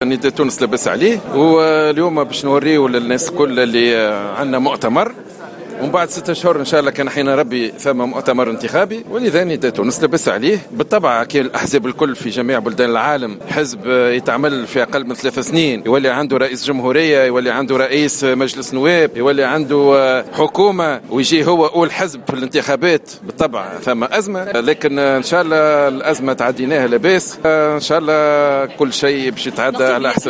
وأكد في تصريحات صحفية على هامش مؤتمر لحزبه في سوسة، أن وضع نداء تونس جيد بالقول باللهجة التونسية: "نداء تونس لاباس عليه".